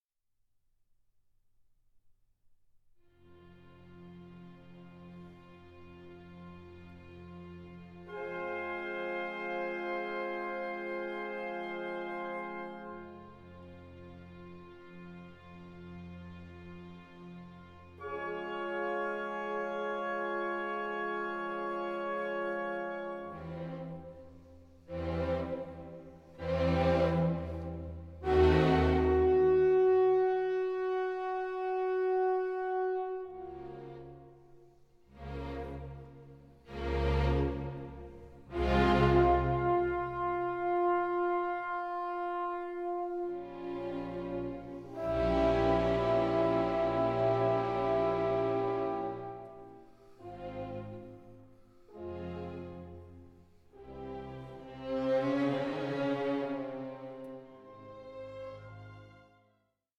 Dialogue
in two studio sessions